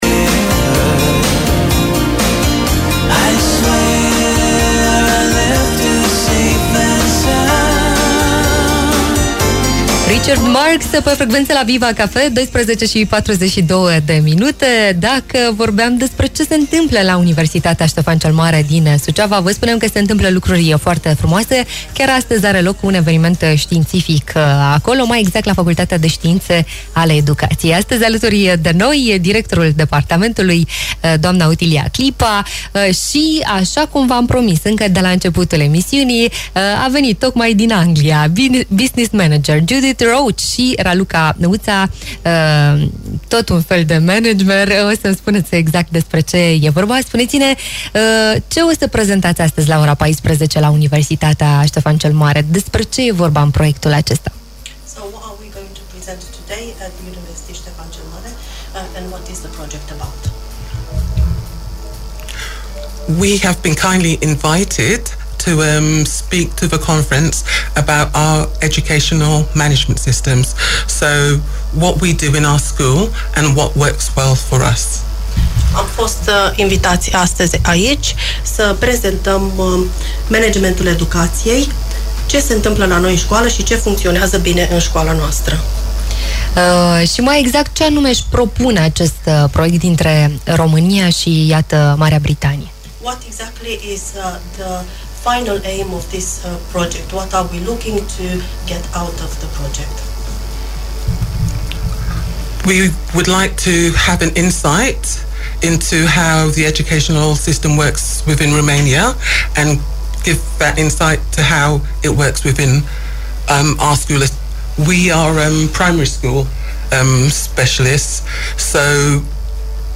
Interviu-sistem-britanic.mp3